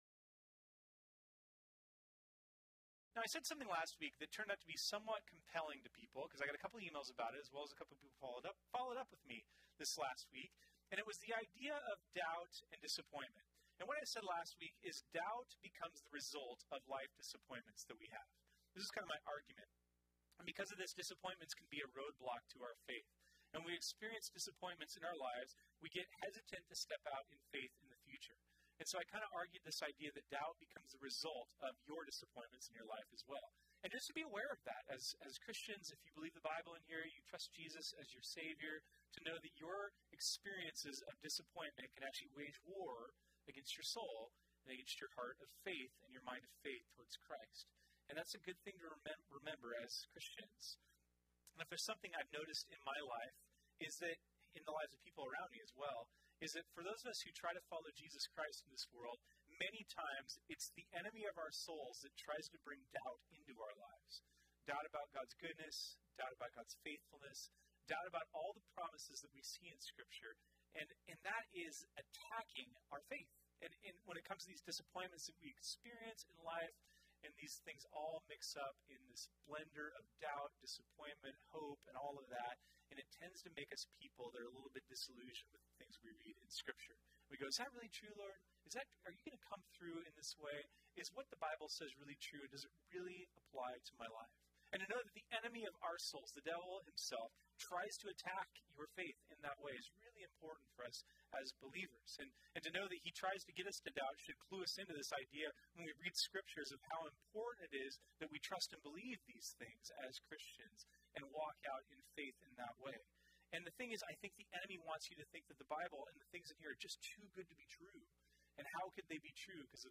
This sermon was originally preached on Sunday, March 3, 2019.